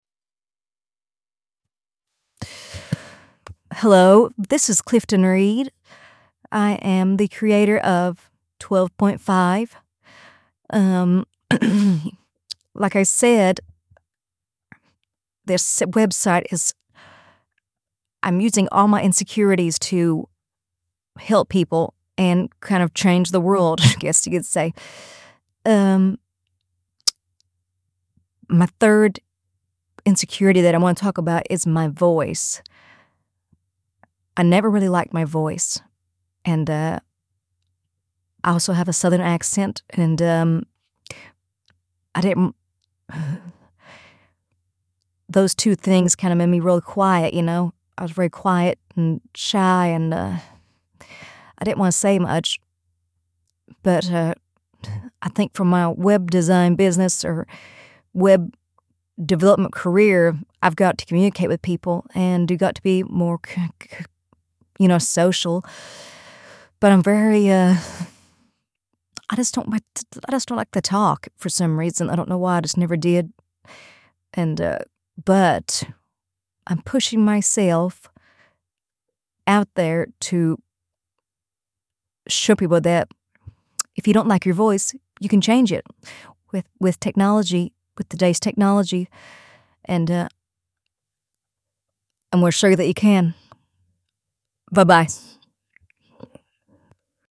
I used a service by Eleven Labs.